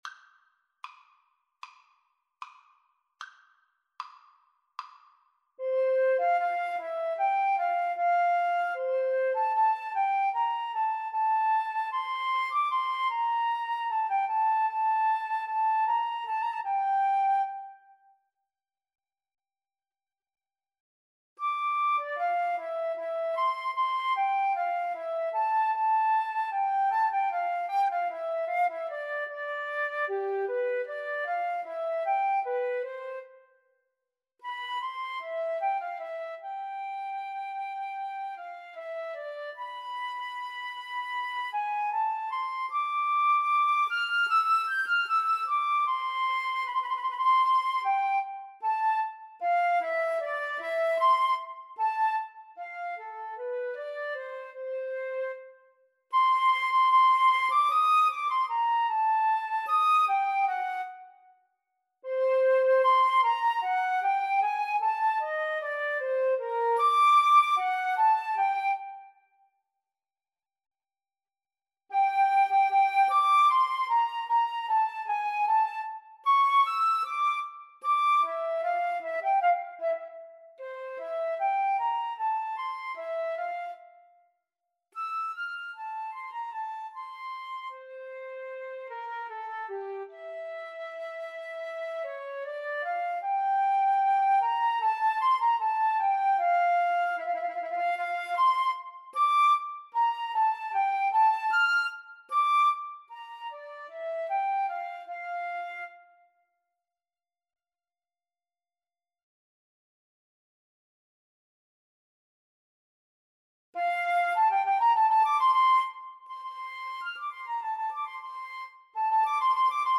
Adagio e sostenuto = 38
4/4 (View more 4/4 Music)
Flute Duet  (View more Intermediate Flute Duet Music)
Classical (View more Classical Flute Duet Music)